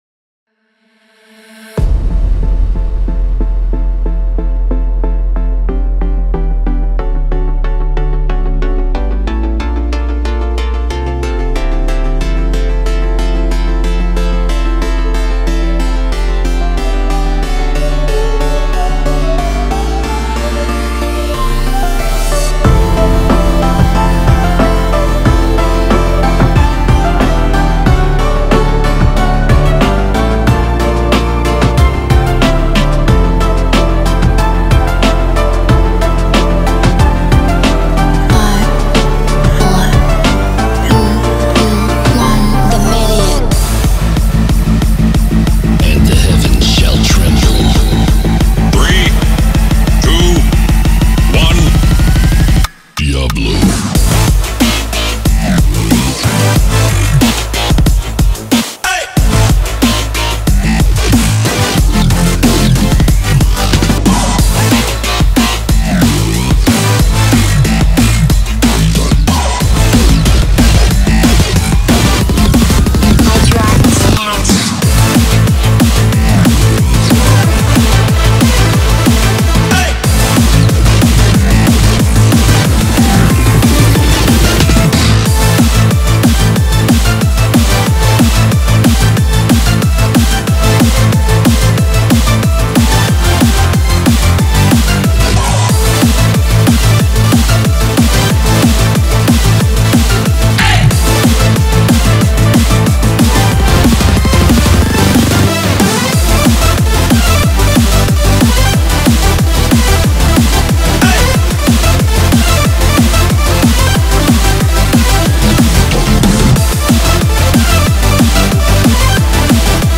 BPM92-184
Audio QualityPerfect (High Quality)
Comments[HARDCORE DUB]